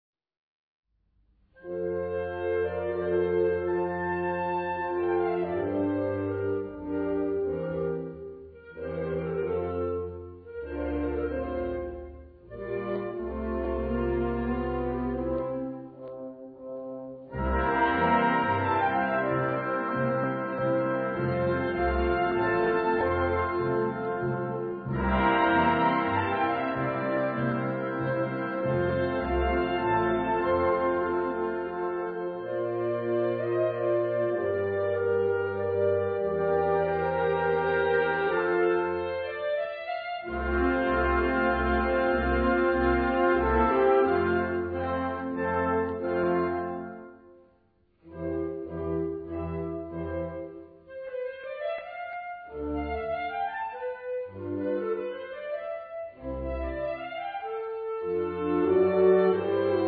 Gattung: Symphonie
Besetzung: Blasorchester